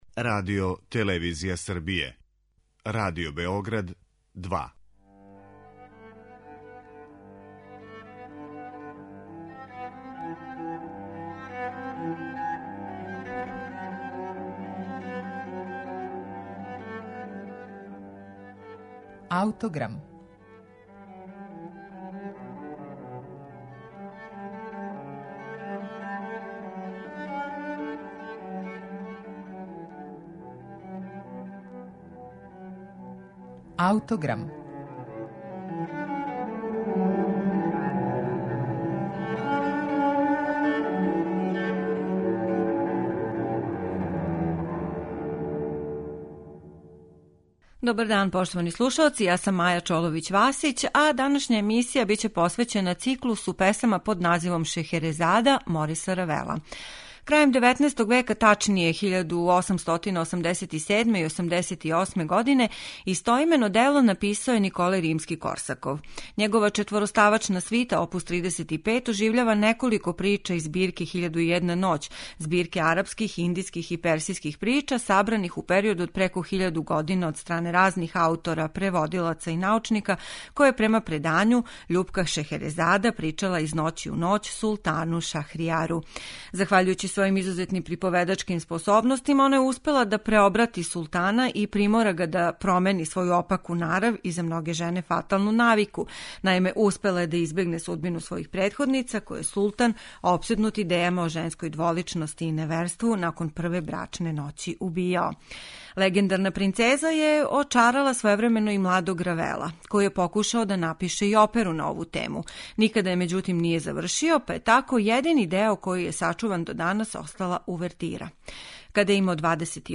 Циклус чине песме Азија, Зачарана свирала и Равнодушан , а слушаћете их у извођењу Џеси Норман и Лондонског симфонијског оркестра, којим диригује Колин Дејвис.